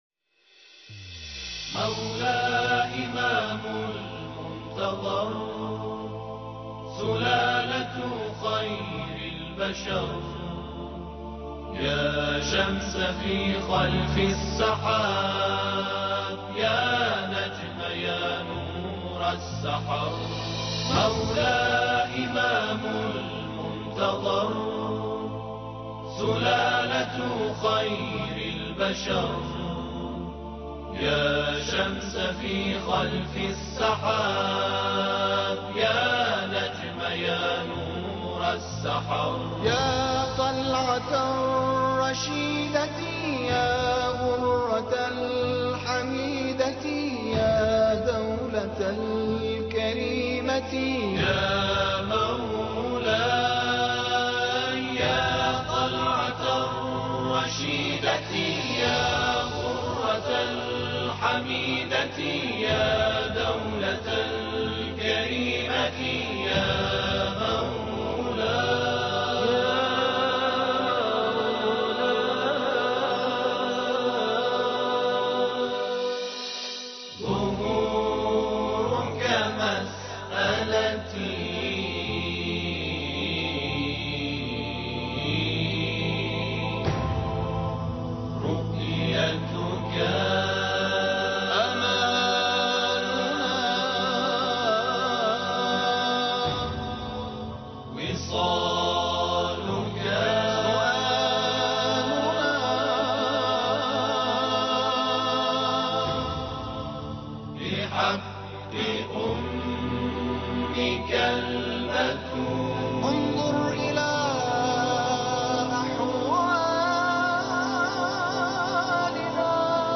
قسمت اول _ اجرای تواشیح - گروه میعاد قم | نیمه شعبان سال 1400 | مسجد مقدس جمکران